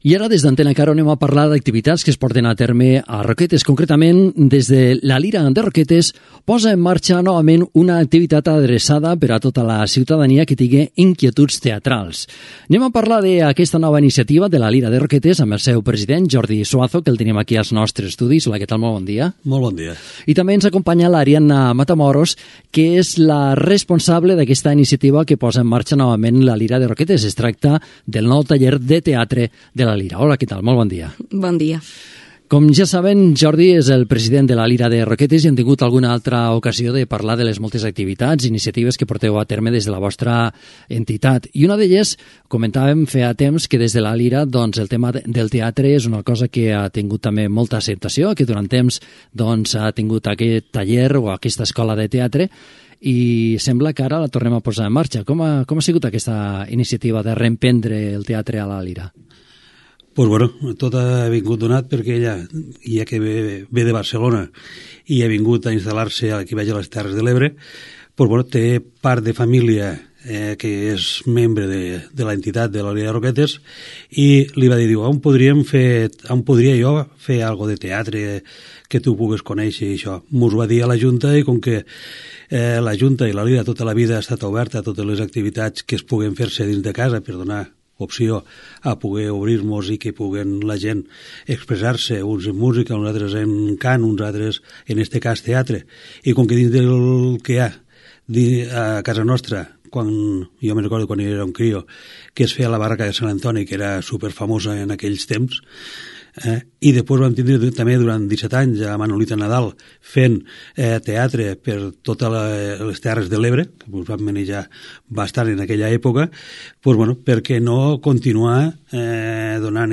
Pública municipal
Entreteniment
FM